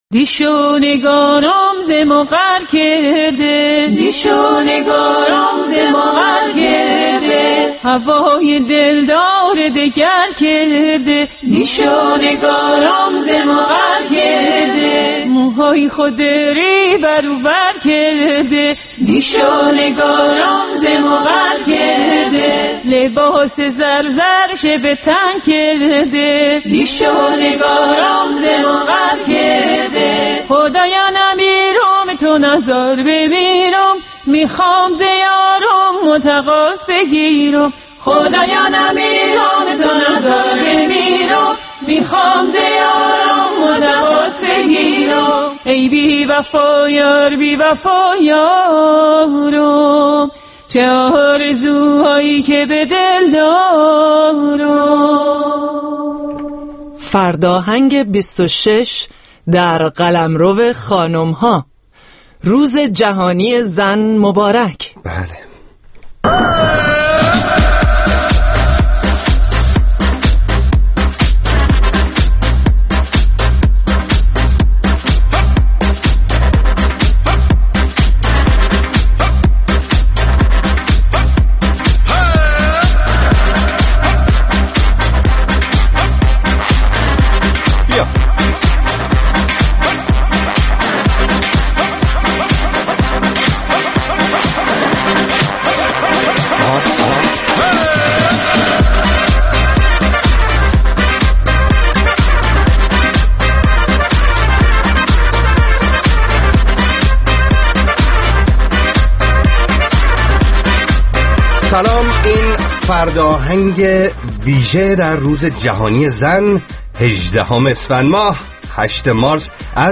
پنجشنبه‌ها از ساعت هشت شب به مدت دو ساعت با برنامه زنده موسیقی رادیو فردا همرا ه باشید.